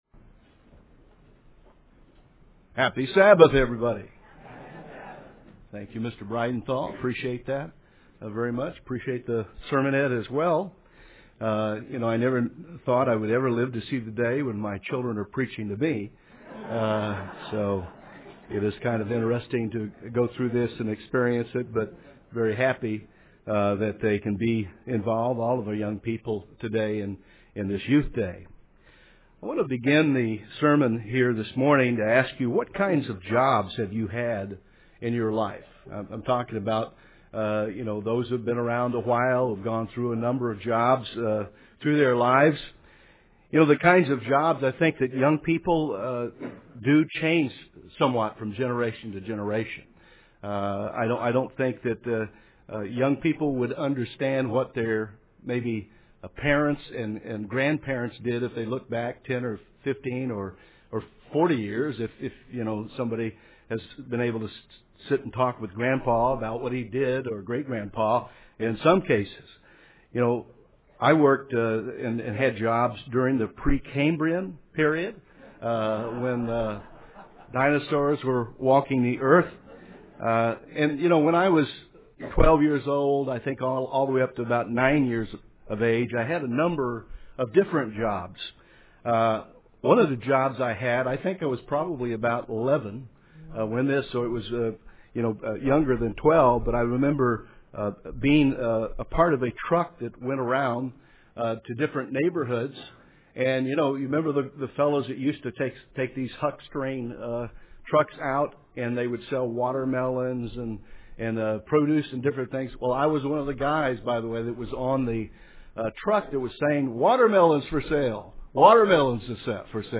This message was given as a Youth Day sermon.